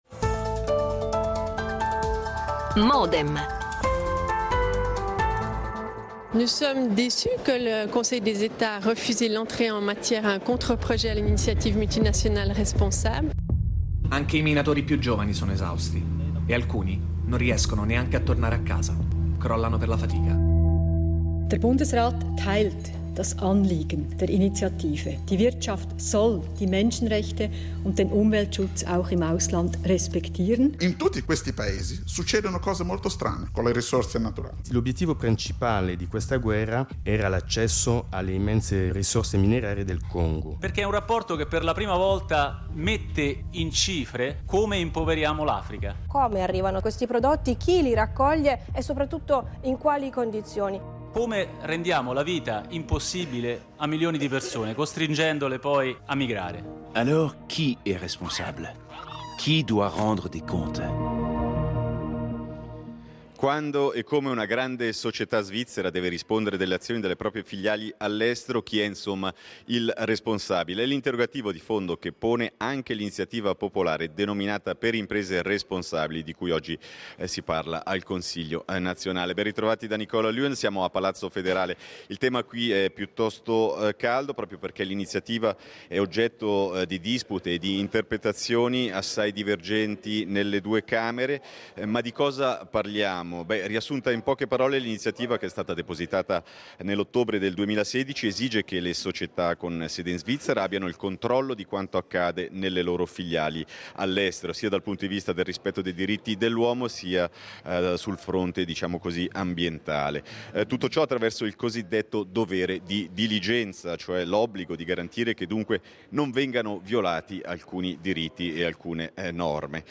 Interviste registrate alla Consigliera nazionale verde Lisa Mazzone e al Consigliere agli Stati Plr Andrea Caroni